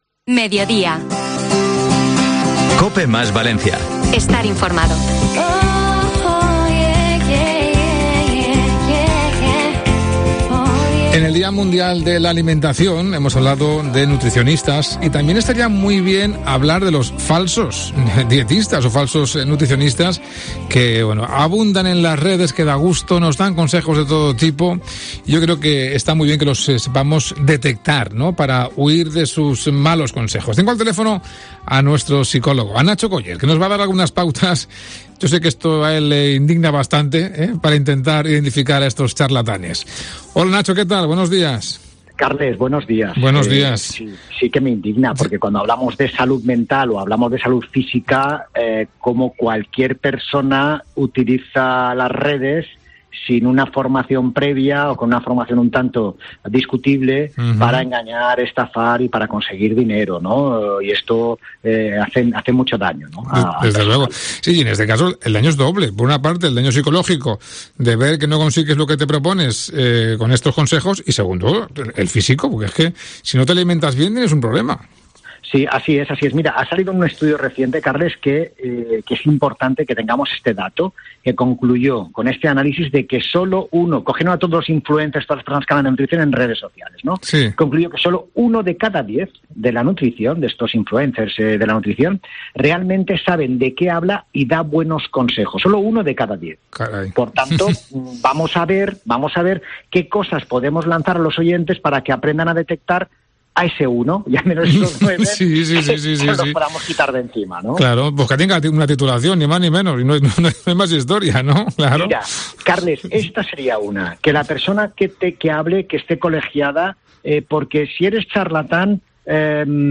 Un experto revela en COPE cómo identificarlos